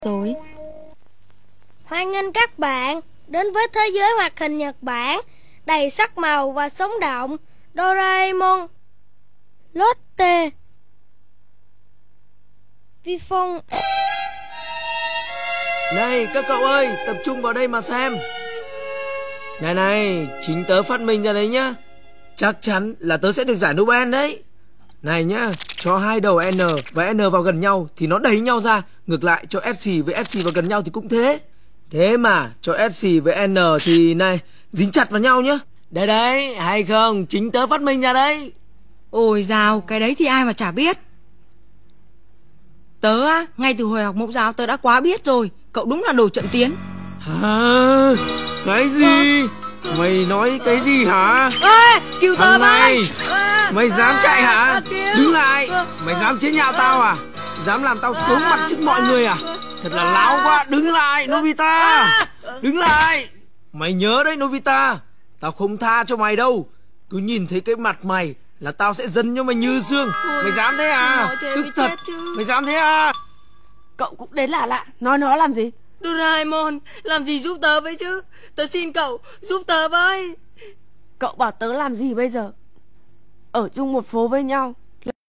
doraemon trên vtv1 những năm 2000 dù lòng tiếng không hay cho lắm, nhưng là 1 ký ức tuổi thơ đẹp